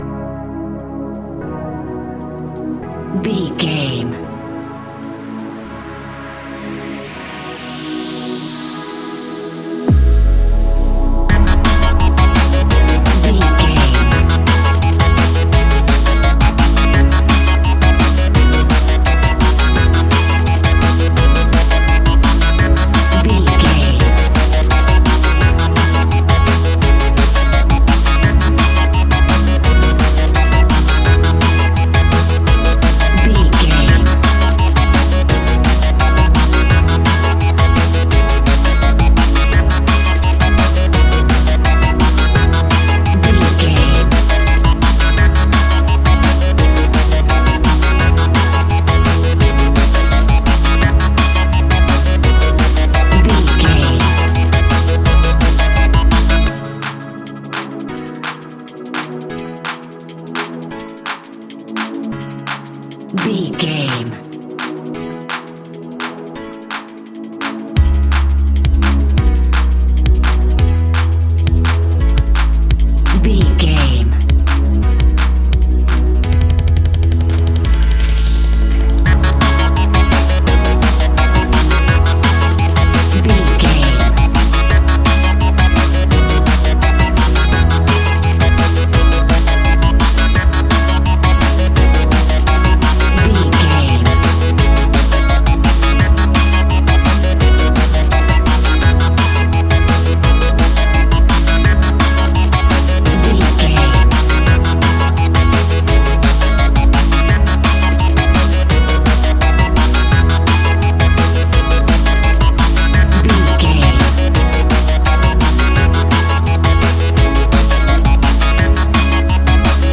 Ionian/Major
electronic
dance
techno
trance
synths
synthwave